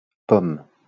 wymowa:
?/i IPA[pɔm]